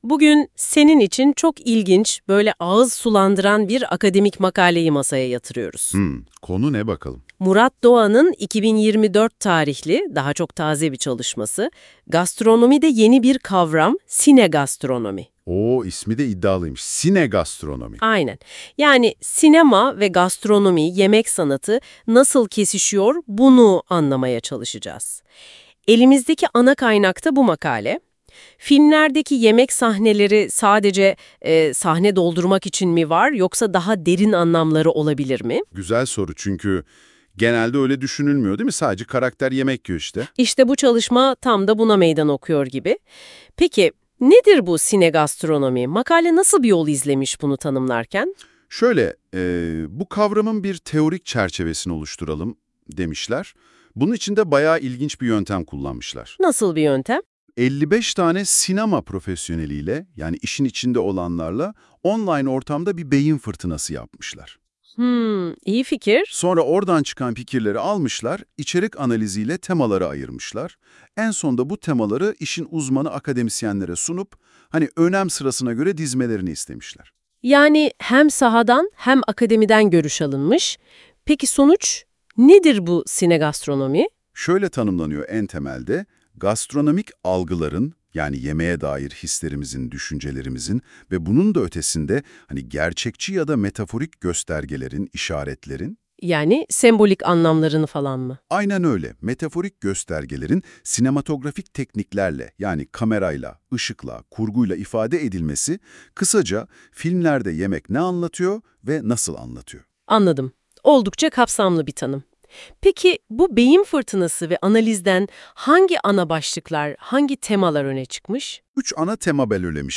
Sinegastronomi, söyleşi